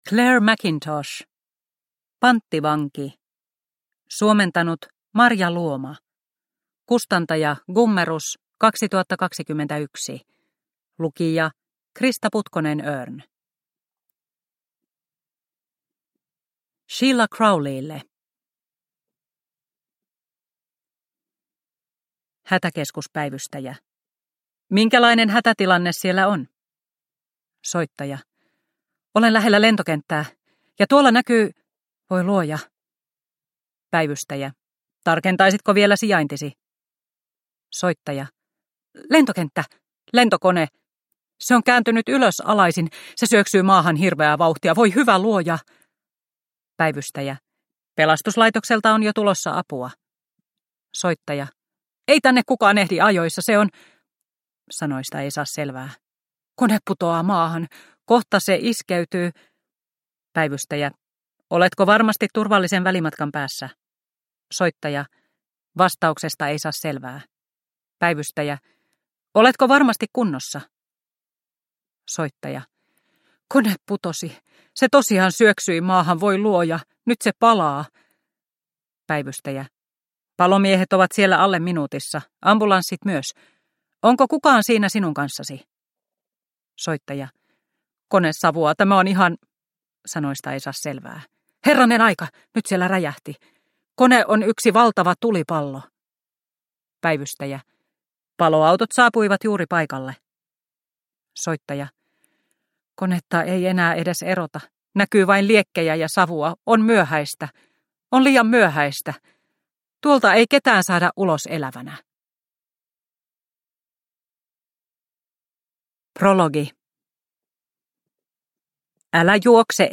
Panttivanki – Ljudbok – Laddas ner